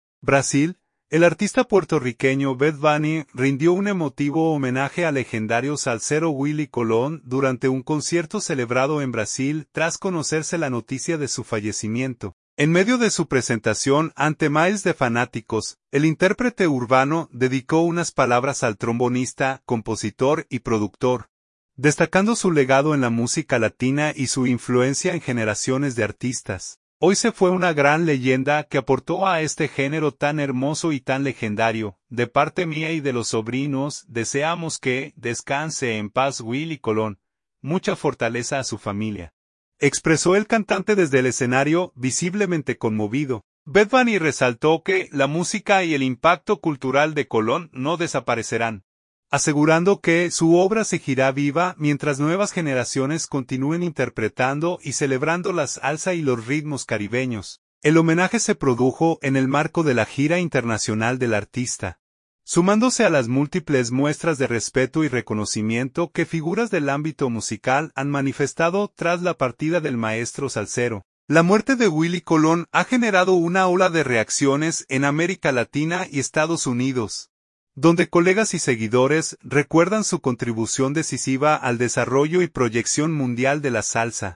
En medio de su presentación ante miles de fanáticos, el intérprete urbano dedicó unas palabras al trombonista, compositor y productor, destacando su legado en la música latina y su influencia en generaciones de artistas.
“Hoy se fue una gran leyenda que aportó a este género tan hermoso y tan legendario. De parte mía y de los sobrinos, deseamos que descanse en paz Willie Colón. Mucha fortaleza a su familia”, expresó el cantante desde el escenario, visiblemente conmovido.